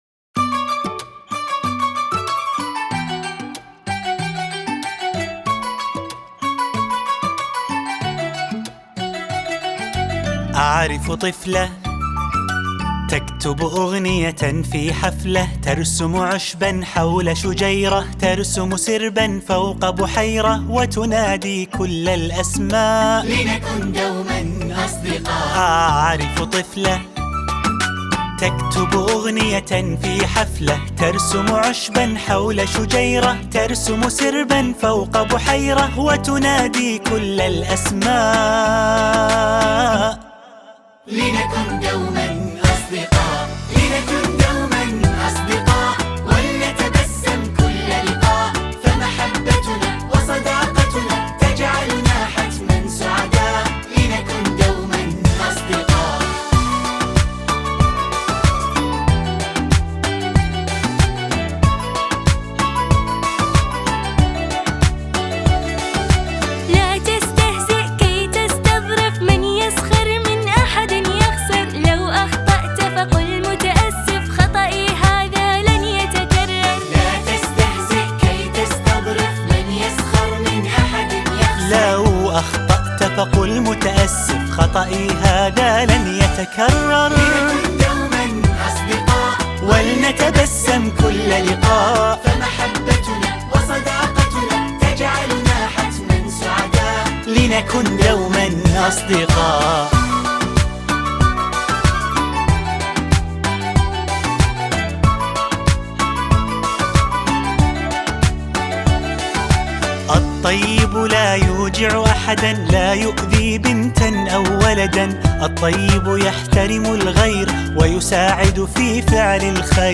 تفاصيل نشيد